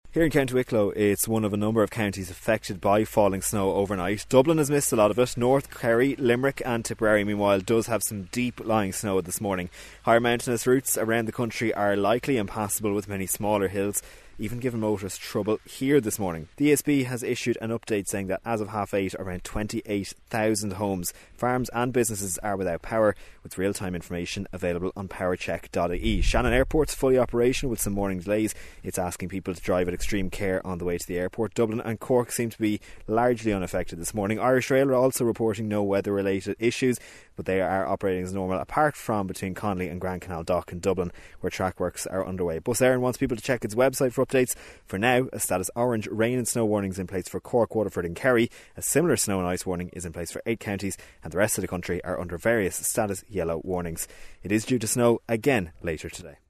Counties worst affected by snow overnight include Limerick, Tipperary, Kilkenny, Carlow, Laois - and Wicklow - from where